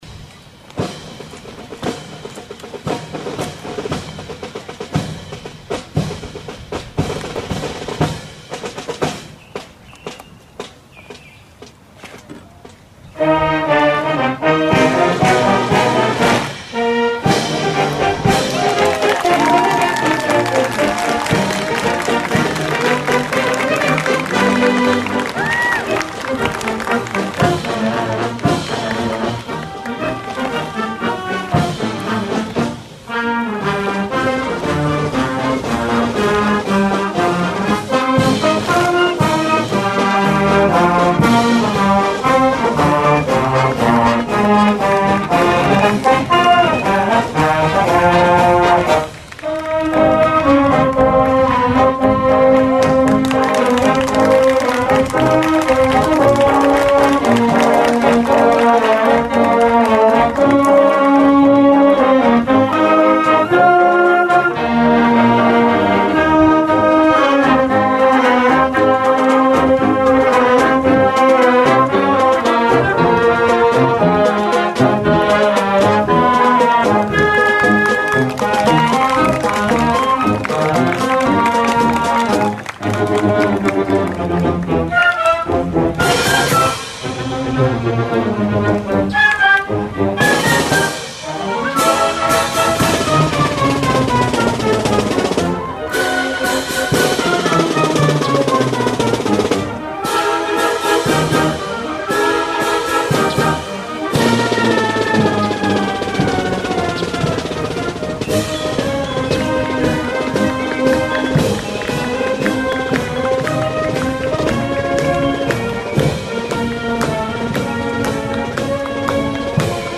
The short journey to the Market House was led by several of the City’s High School Marching Bands who serenaded Her Grace and the jubilant crowds lining the route.